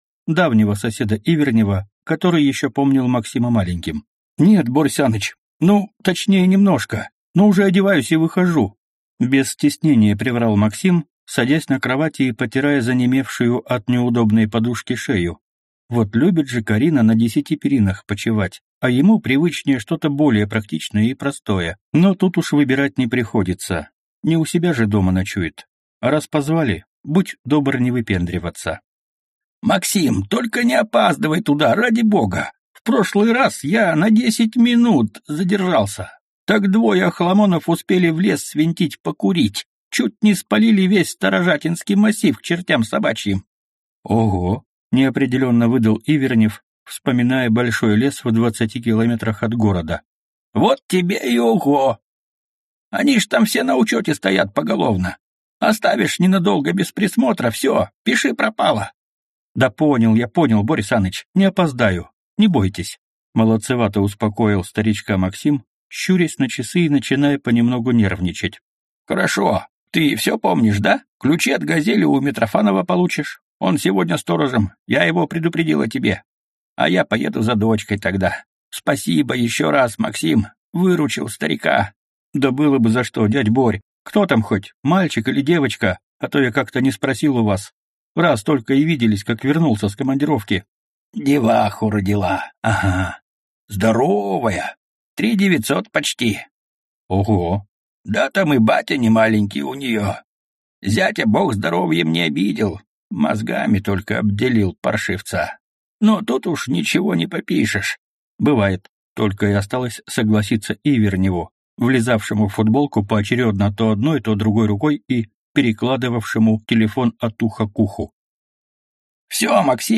Аудиокнига S-T-I-K-S. Дорога в рай | Библиотека аудиокниг
Прослушать и бесплатно скачать фрагмент аудиокниги